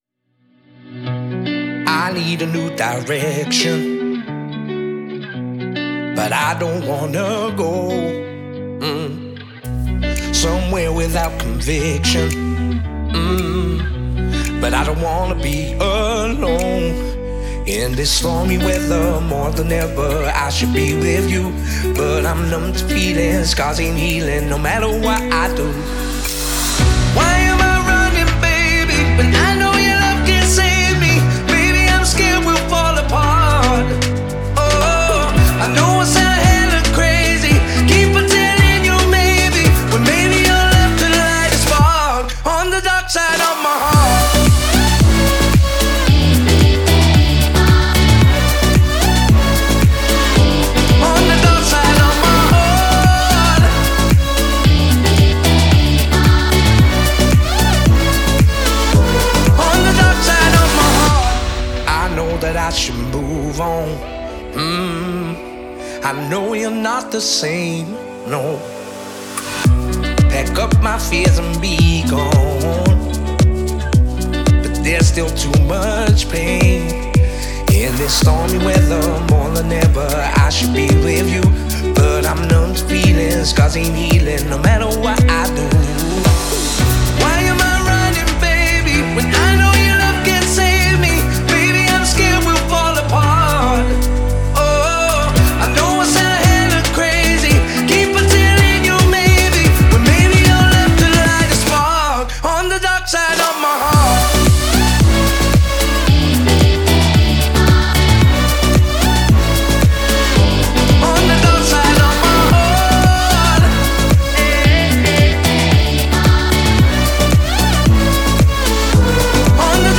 это проникновенная поп-баллада с элементами соула.